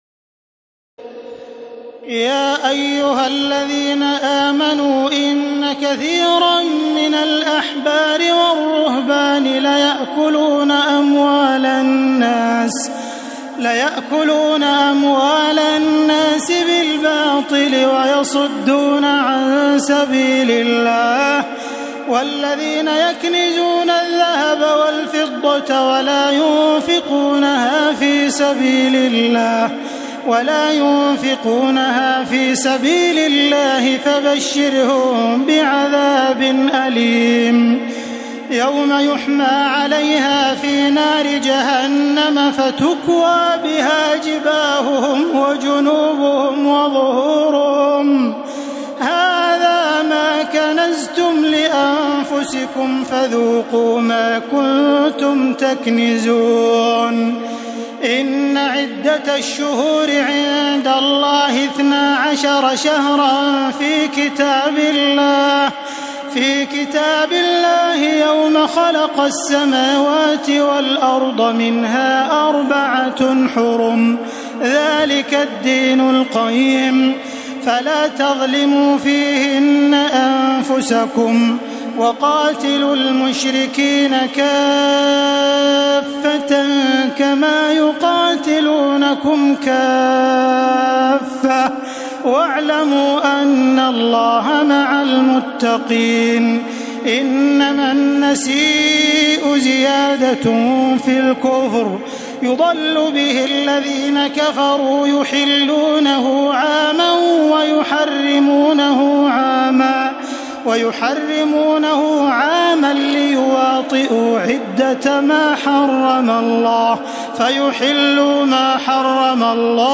تراويح الليلة التاسعة رمضان 1432هـ من سورة التوبة (34-93) Taraweeh 9 st night Ramadan 1432H from Surah At-Tawba > تراويح الحرم المكي عام 1432 🕋 > التراويح - تلاوات الحرمين